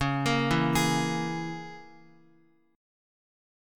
Dbm6 Chord